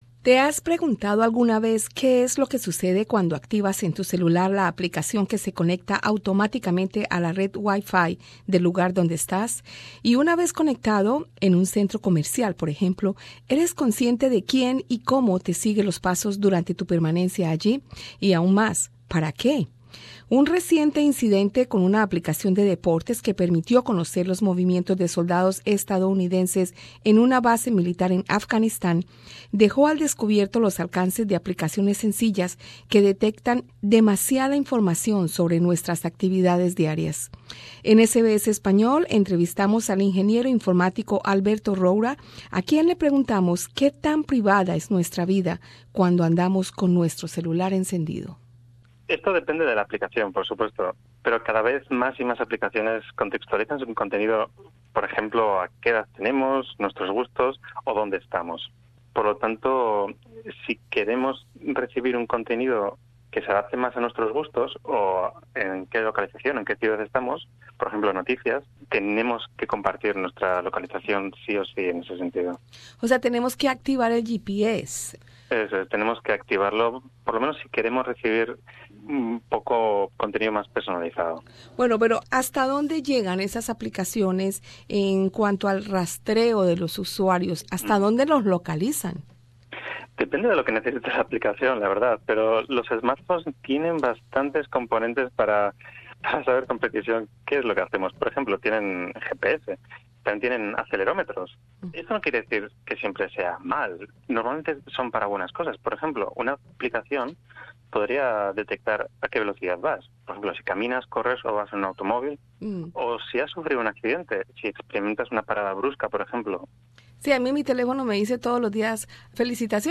Un reciente incidente con una aplicación de deportes que permitió conocer los movimientos de soldados estadounidenses en una base militar en Afganistán, dejó al descubierto los alcances de aplicaciones sencillas que detectan “demasiada” información sobre nuestras actividades diarias. Escucha arriba en el podcast la entrevista con el ingeniero informático